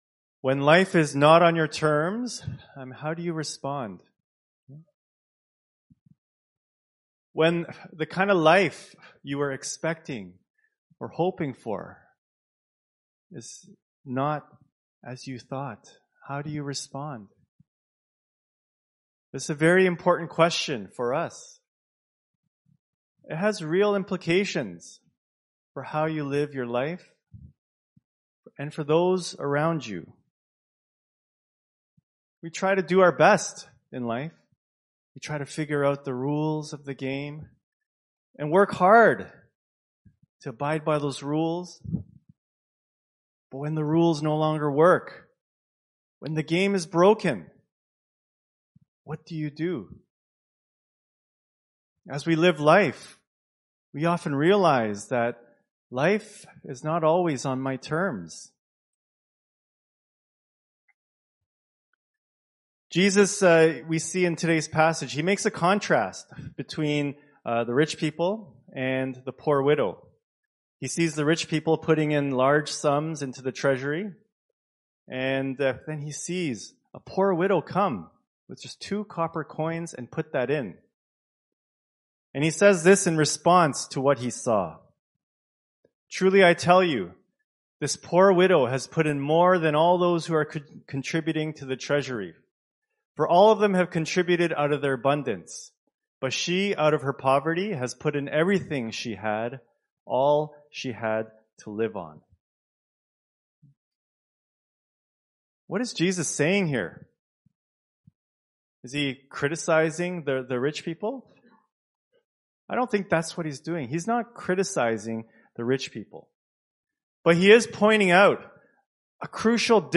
Scripture Passage Mark 12:38-44 Worship Video Worship Audio Sermon Script When life is not on your terms, how do you respond?